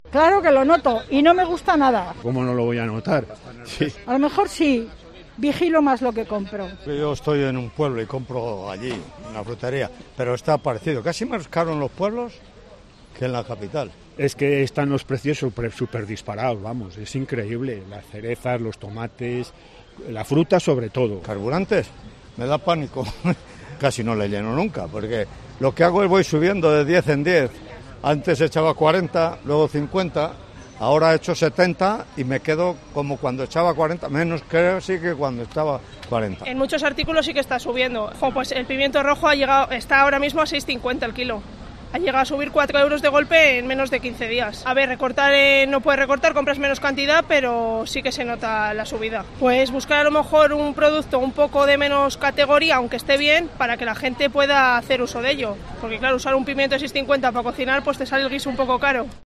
En el mercado al aire libre ubicado en la Plaza de España, en pleno centro de Valladolid, los clientes no ocultan su enfado.
Un pensionista, bolsas de la compra en mano, revisa el cambio antes de introducirlo en su cartera.